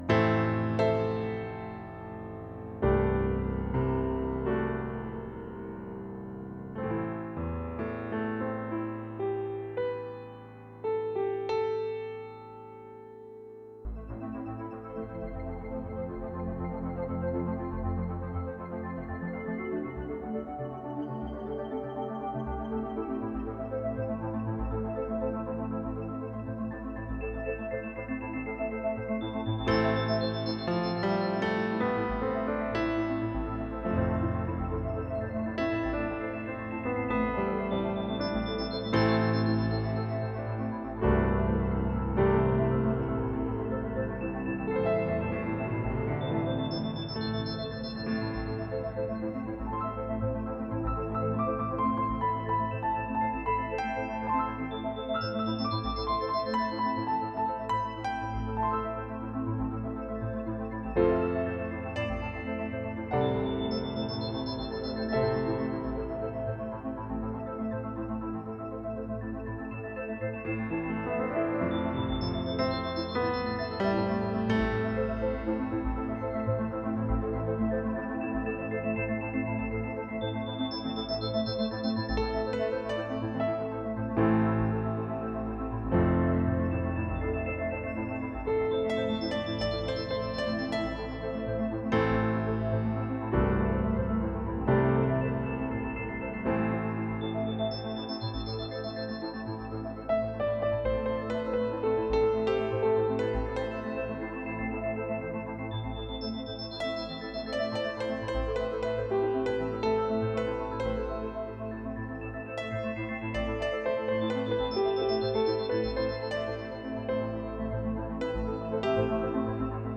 Created live
Yamaha digital piano